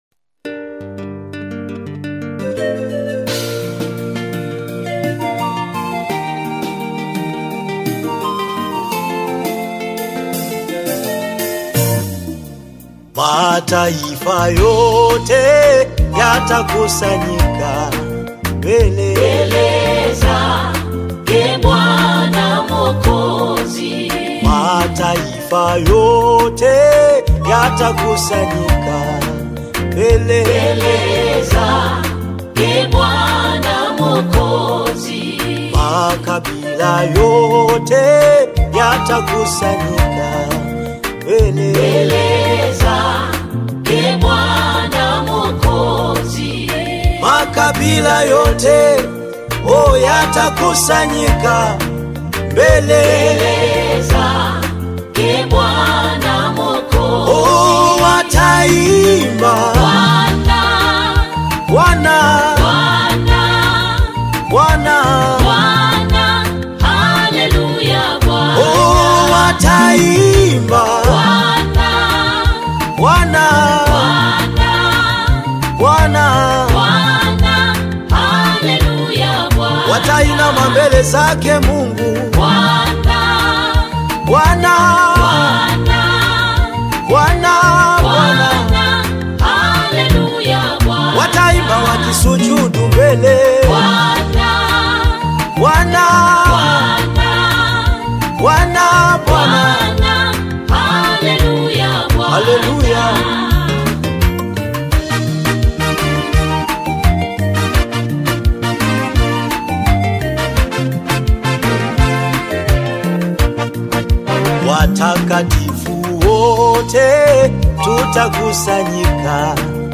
Tanzanian gospel music
a spirit-filled worship song